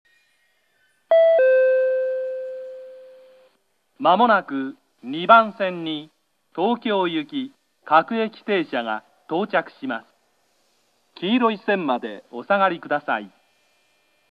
２番線接近放送
自動放送は京葉線ＰＲＣ型放送でした。
kemigawahama2bansen-sekkin.mp3